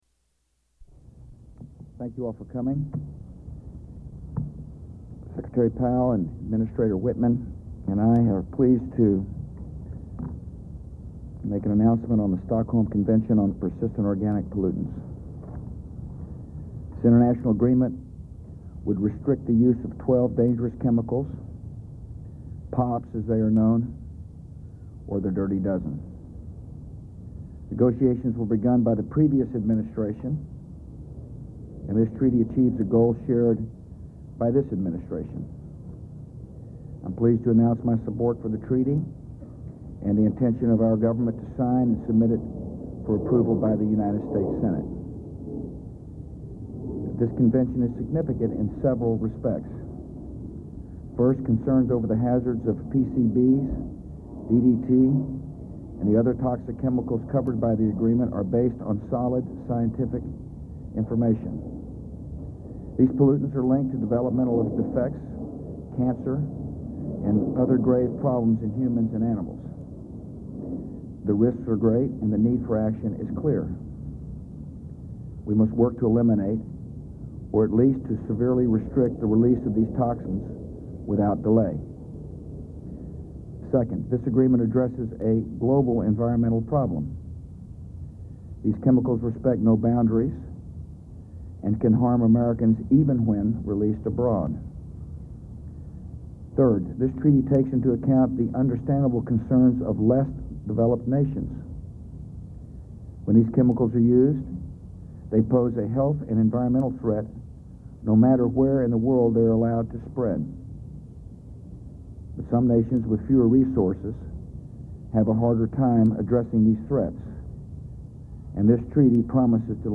U.S. President George W. Bush announces the admisnistration's support of the Stockholm Convention on Persistent Organic Pollutants